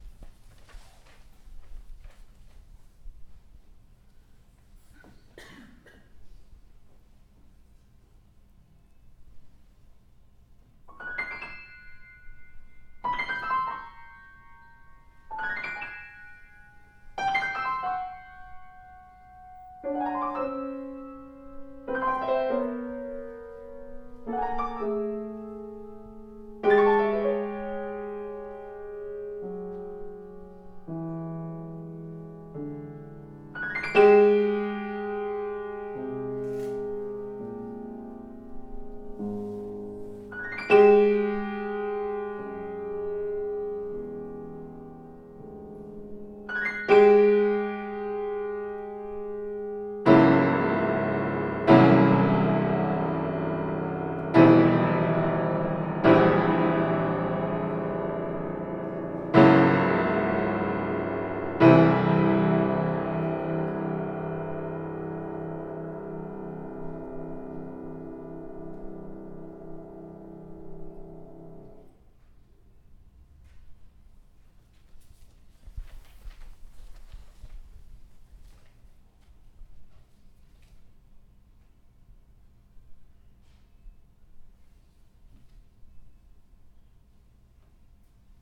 Composition par des élèves de la classe d'une musique pour piano, à partir d'un poème japonais (haikai):
Audition le 18 mai 2010 aux Champs-Libres, pièces jouées par deux élèves de la classe d'accompagnement du Conservatoire